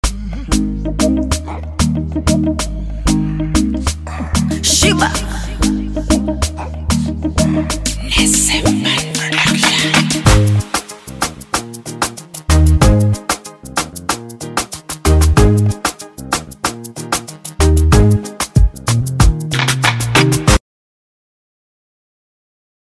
• Gênero: Pop